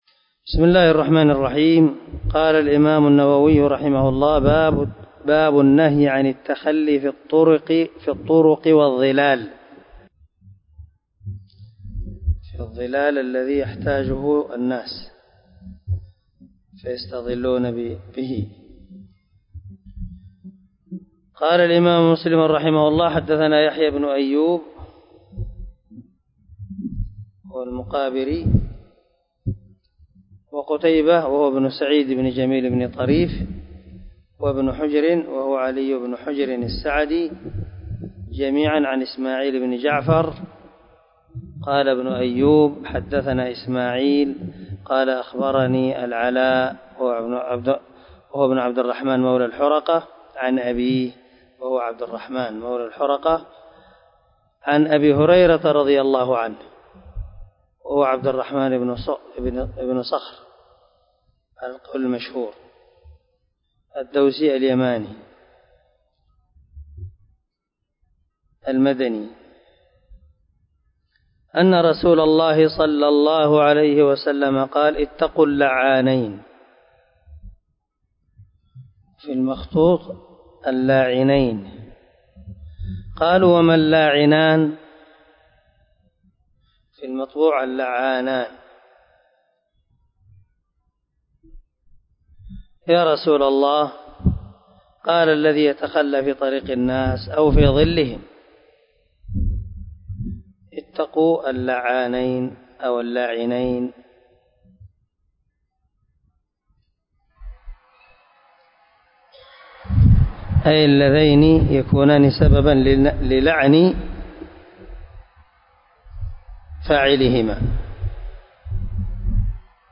202الدرس 30 من شرح كتاب الطهارة حديث رقم ( 269 ) من صحيح مسلم
دار الحديث- المَحاوِلة- الصبيحة.